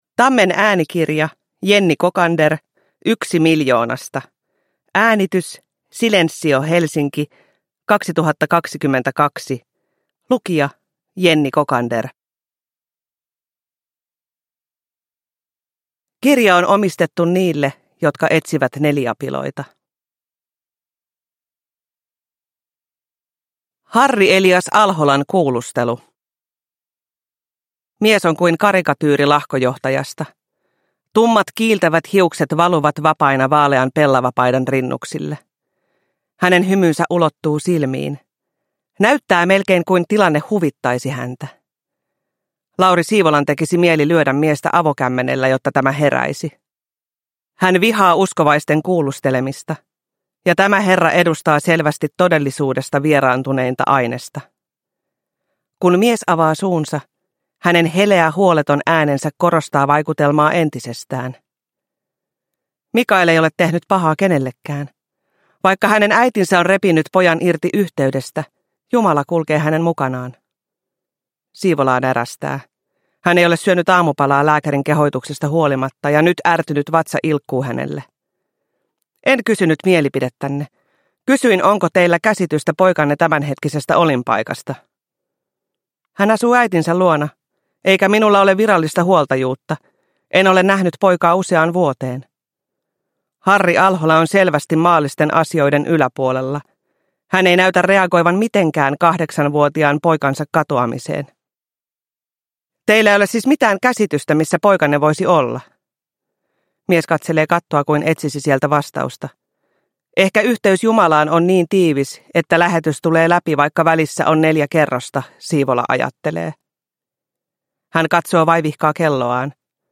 Yksi miljoonasta – Ljudbok – Laddas ner
Uppläsare: Jenni Kokander